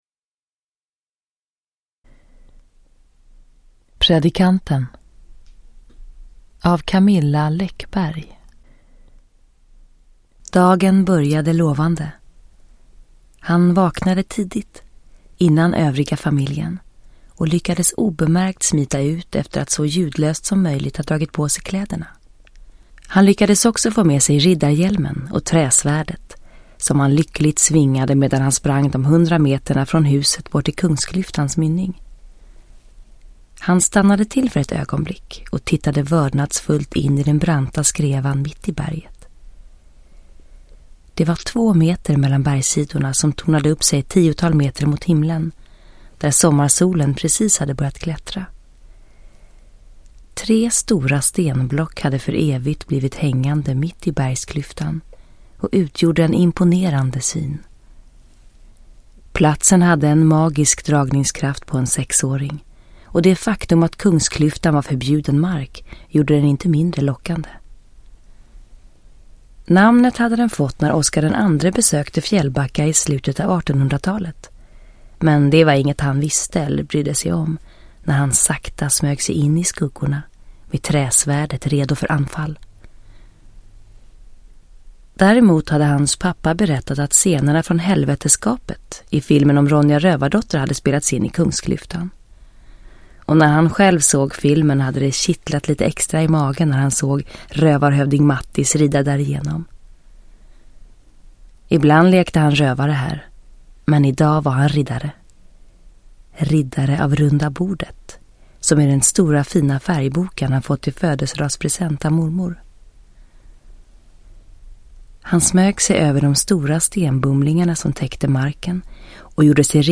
Downloadable Audiobook
Eva Röse